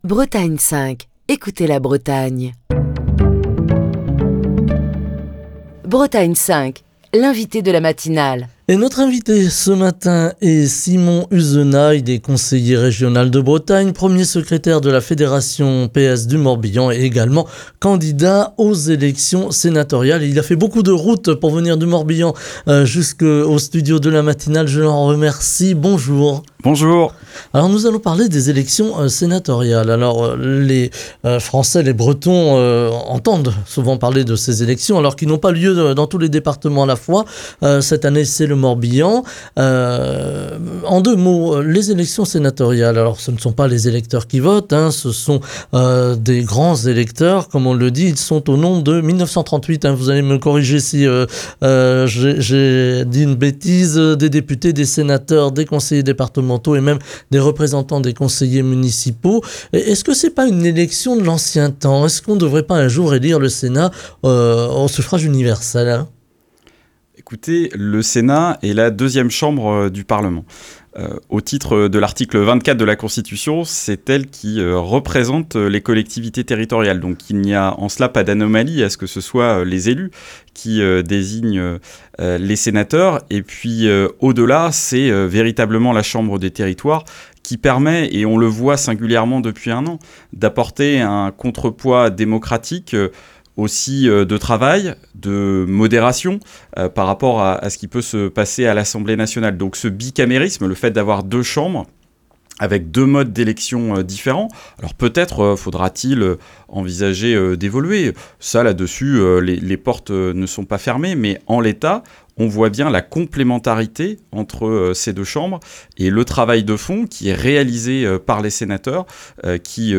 Ce mercredi, Simon Uzenat, Conseiller régional de Bretagne, Premier secrétaire de la fédération PS du Morbihan et candidat aux élections sénatoriales est l'invité de la matinale de Bretagne 5.